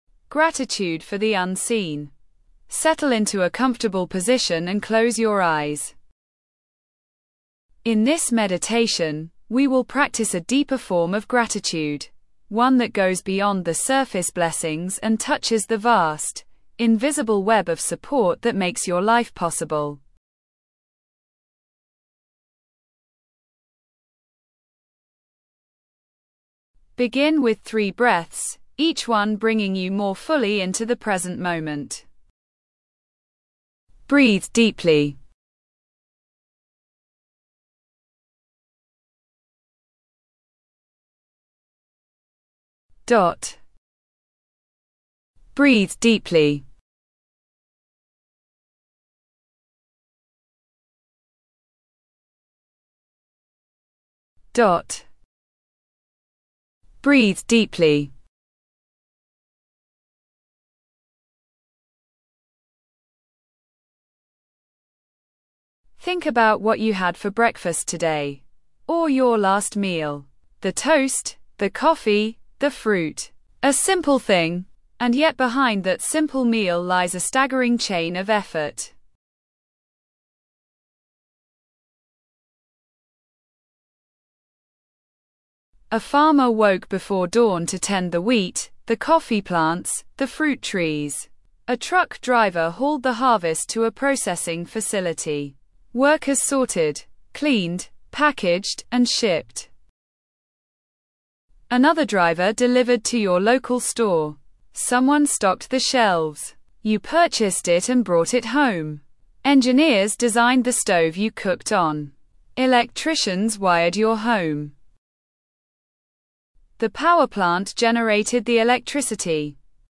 Gratitude for the Unseen: Appreciation Meditation | Positivity